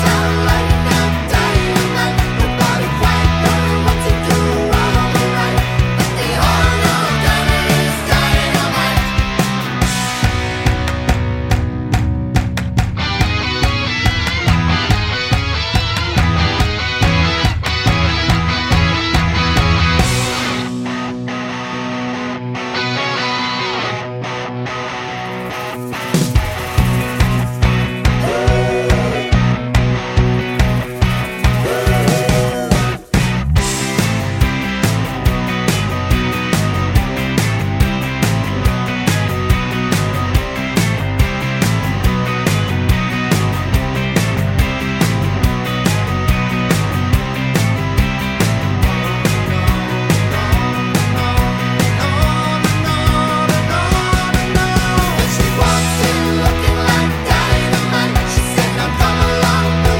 no Backing Vocals Glam Rock 3:01 Buy £1.50